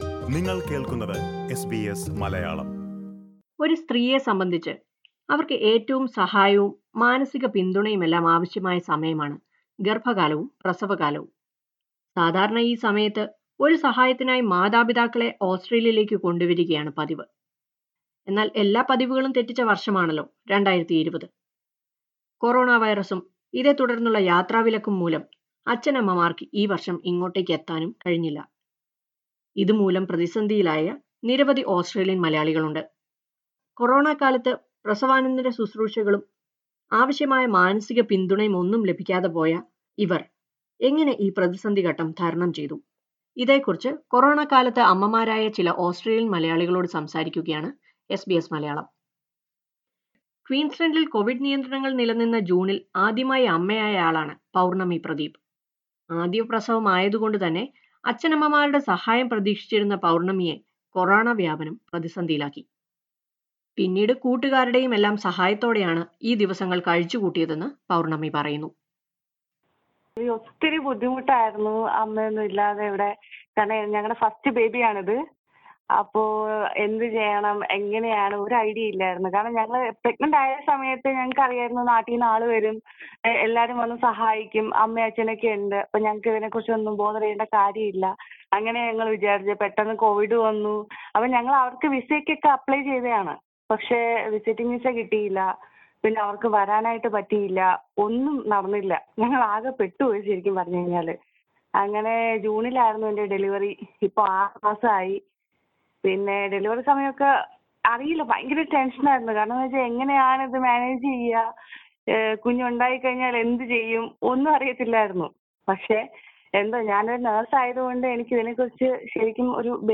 കൊറോണക്കാലത്ത് അമ്മയായ ഓസ്‌ട്രേലിയൻ മലയാളികൾക്ക് കേരളത്തിൽ നിന്ന് അച്ഛനമ്മമാരെ കൊണ്ടുവരാൻ കഴിഞ്ഞില്ല. അമ്മമാരുടെ സഹായമില്ലാതെ പ്രസവാനന്തര ശുശ്രൂഷകളും ശരിയായ വിശ്രമവുമൊന്നും ലഭിക്കാൻ കഴിയാത്തതിന്റെ മാനസിക ബുദ്ധിമുട്ടിലാണ് പലരും. ഈ പ്രതിസന്ധി ഘട്ടം എങ്ങനെ തരണം ചെയ്തു എന്നതിനെക്കുറിച്ച് കൊറോണക്കാലത്ത് അമ്മമാരായ ചിലർ സംസാരിക്കുന്നത് കേൾക്കാം ...